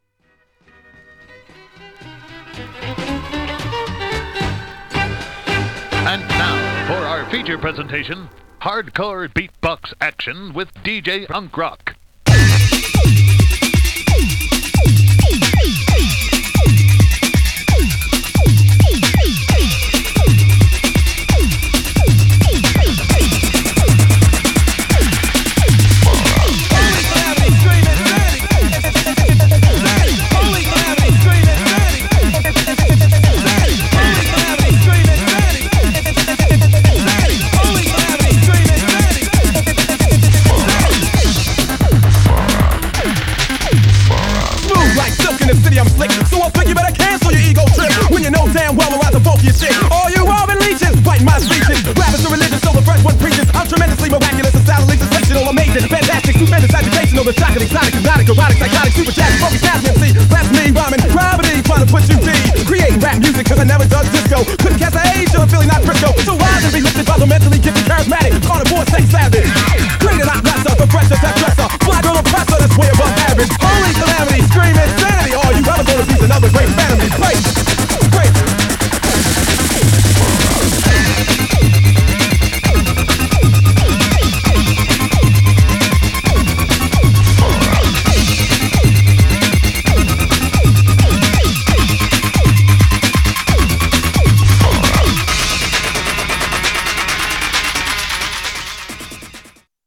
Styl: Electro, Breaks/Breakbeat